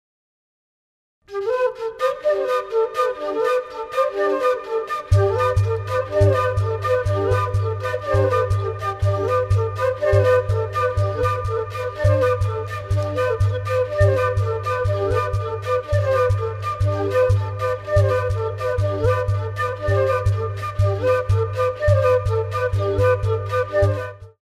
Kalimba & other instruments